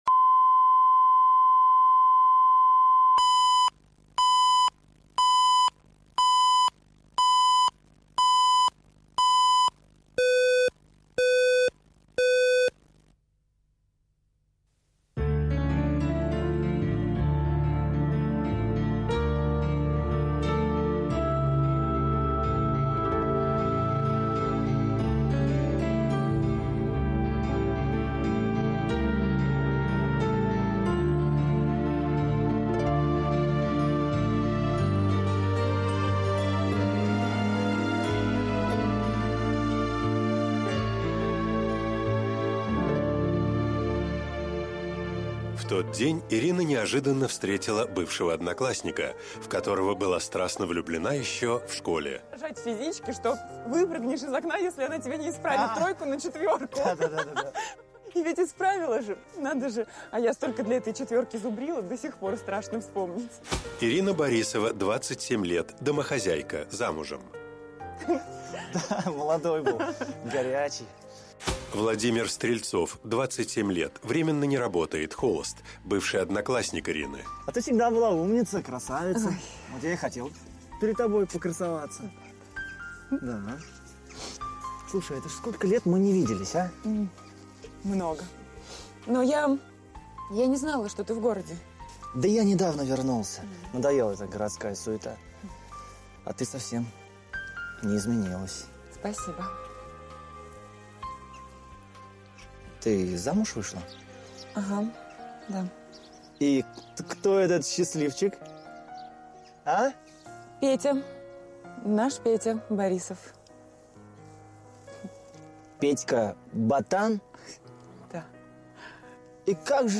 Аудиокнига Я выбираю тебя | Библиотека аудиокниг